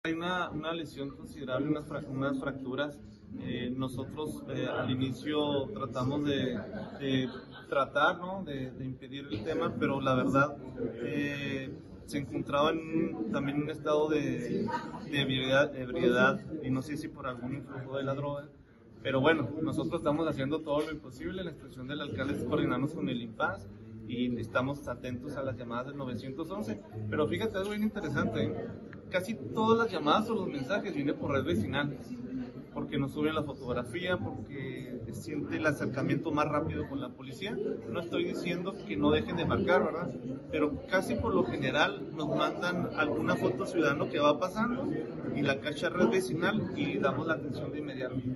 Julio César Salas, director de Seguridad Pública Municipal, señaló que elementos llegaron con el individuo antes de que atentara contra su propia vida, sin embargo no lo lograron.